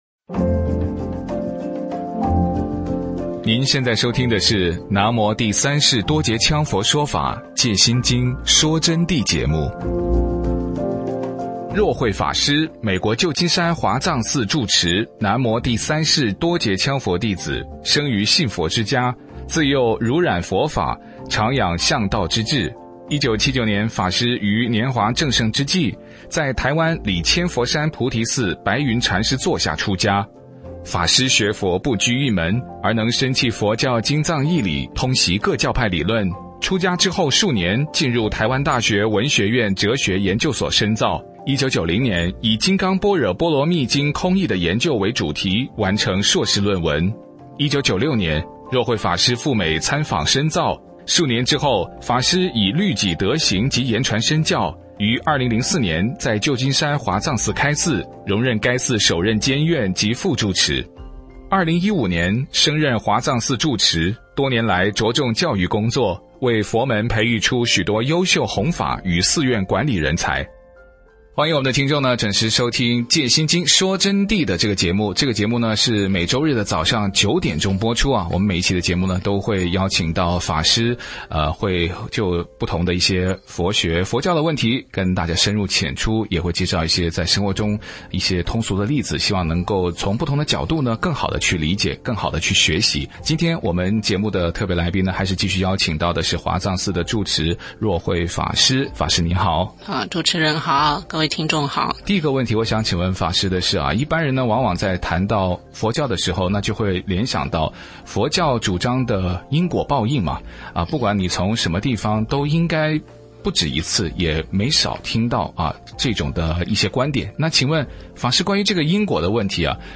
佛弟子访谈（十三）浅谈因果业报和忏悔